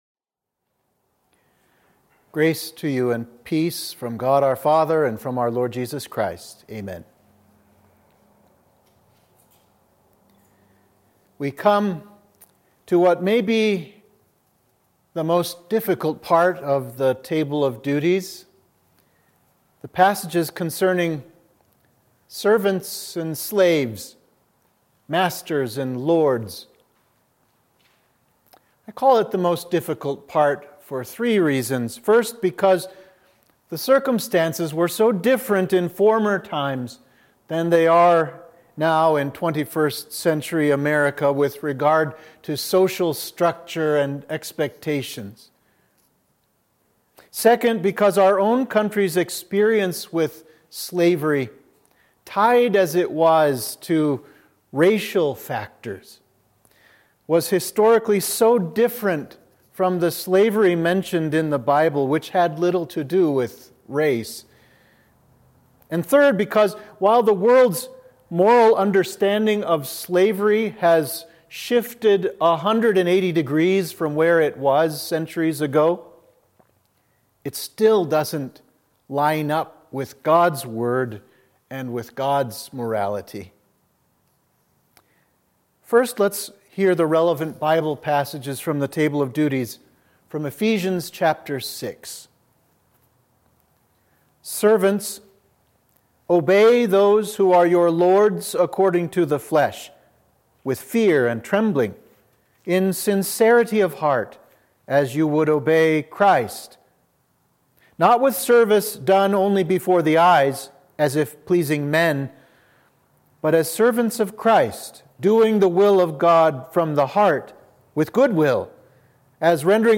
Sermon for Midweek of Laetare – Lent 4